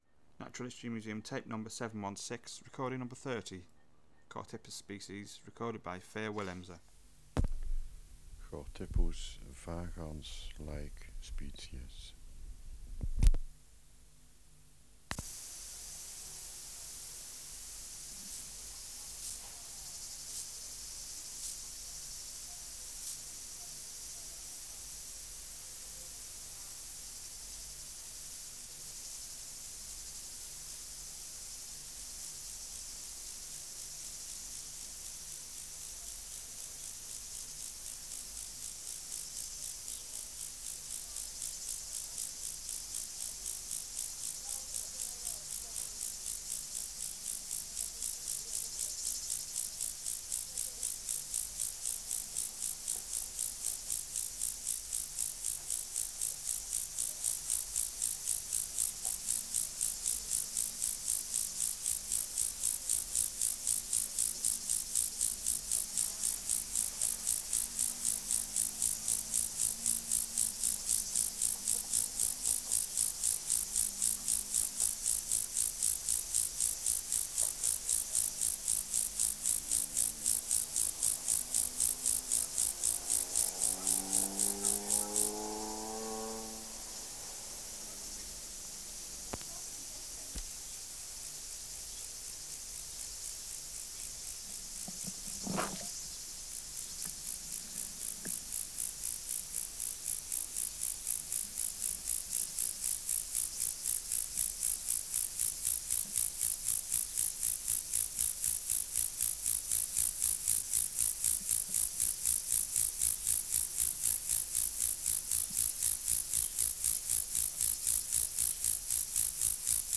Project: Natural History Museum Sound Archive Species: Chorthippus